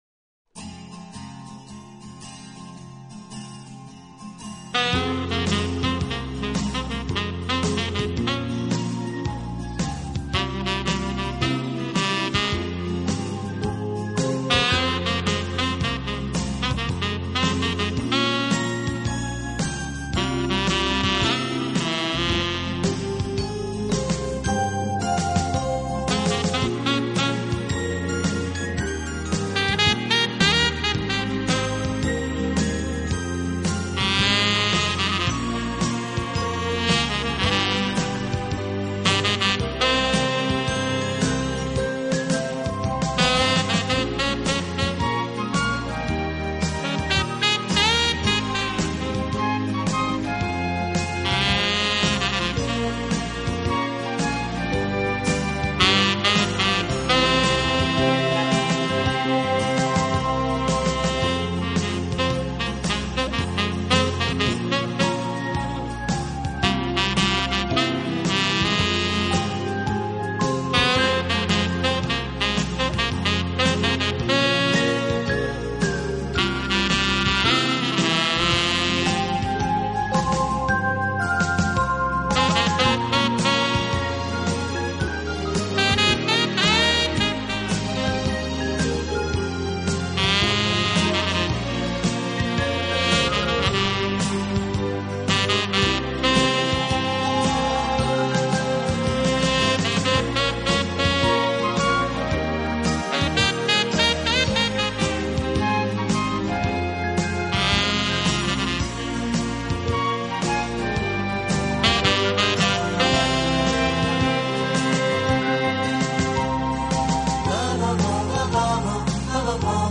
巨大力量，总的来说，它的柔和优美的音色，具有弦乐器的歌唱风格。